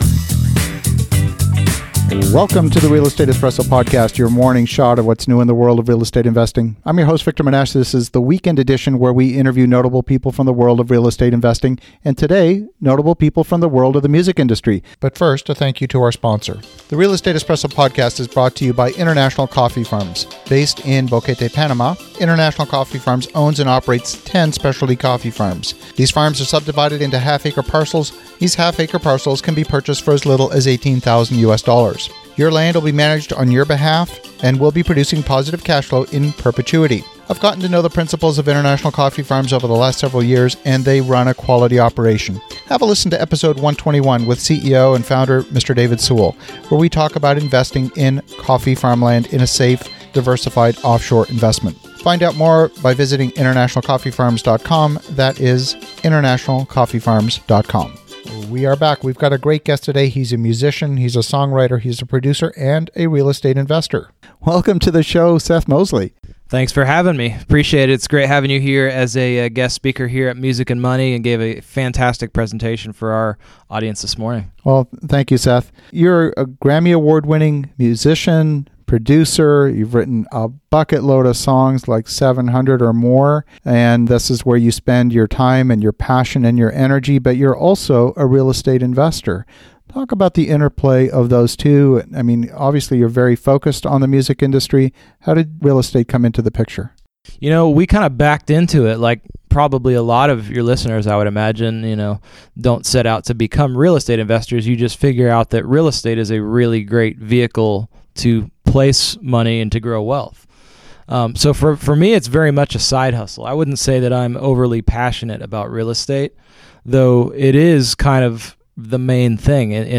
Special Guest